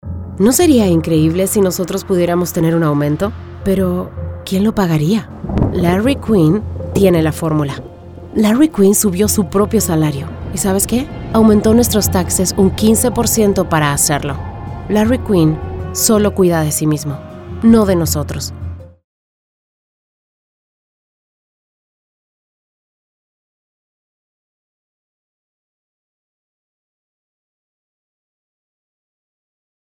Female Spanish Republican Political Voiceover
Spanish attack ad